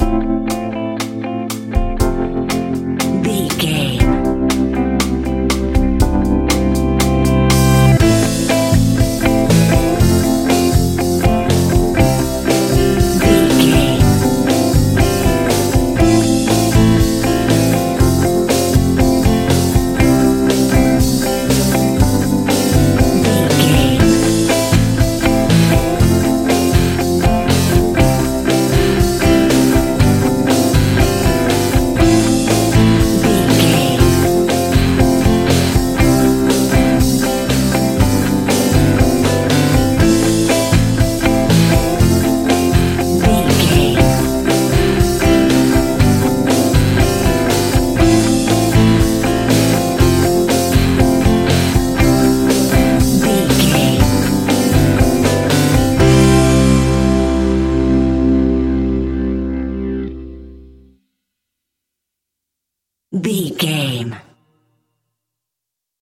Uplifting
Ionian/Major
fun
energetic
motivational
cheesy
instrumentals
guitars
bass
drums
piano
organ